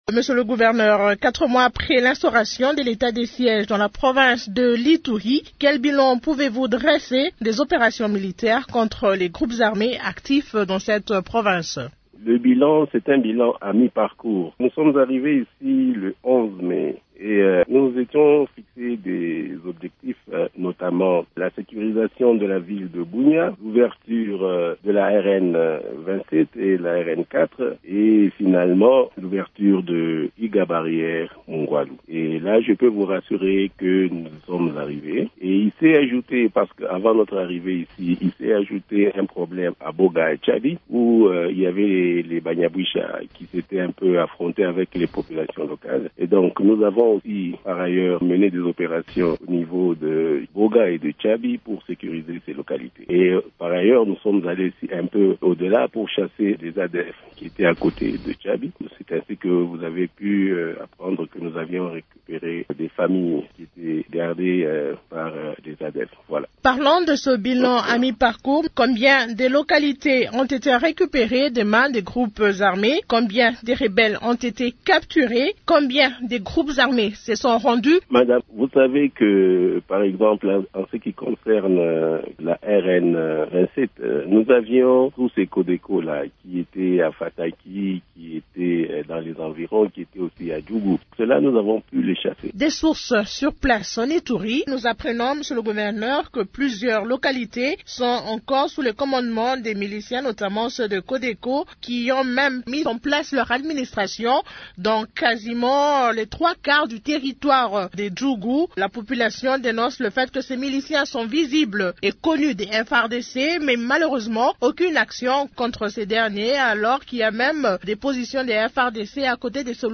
Le gouverneur de l’Ituri, le lieutenant général Johny Nkashama Luboya l’a affirmé jeudi 2 septembre, dans une interview exclusive accordée à Radio Okapi.